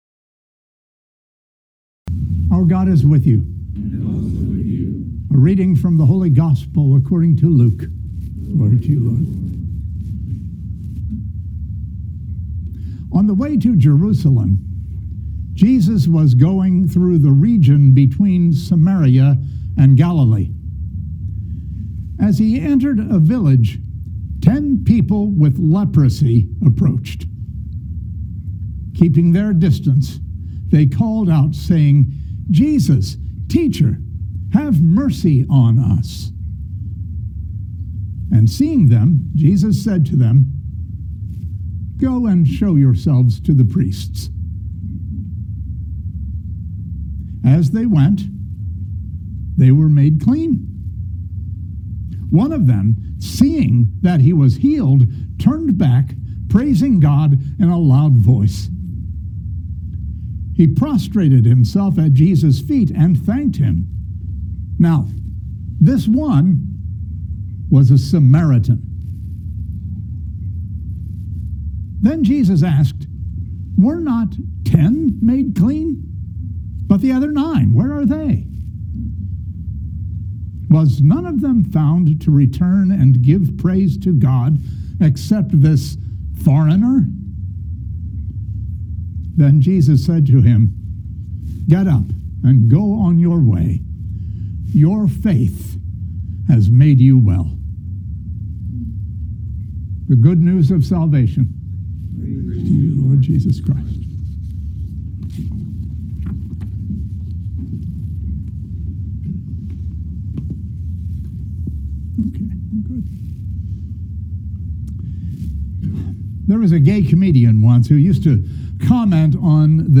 Living Beatitudes Community Homilies: Faith, Gratitude, and Jesus the Advocate